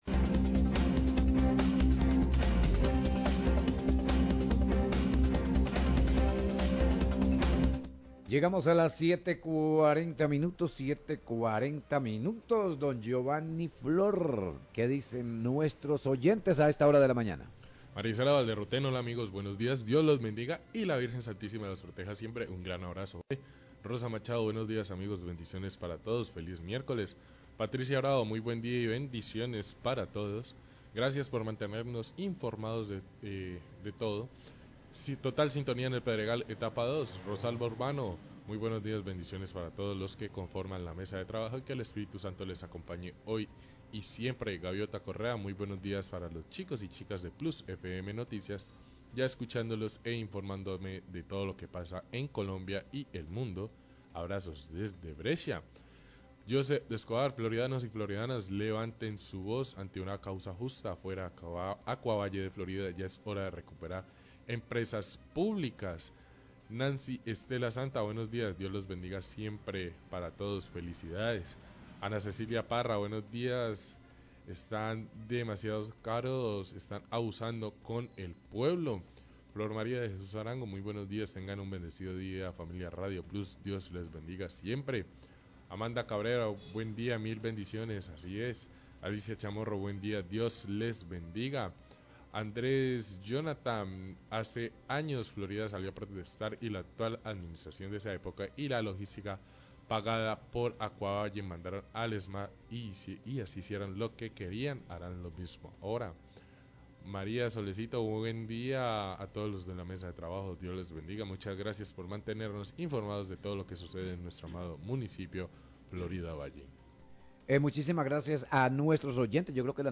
Oyente propone realizar plantones en oficinas de empresas prestadoras de servicio en Florida, Pluss FM 96.0, 740am
Radio